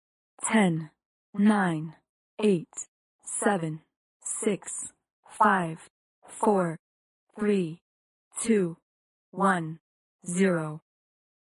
Звуки таймера
Countdown from 10 in English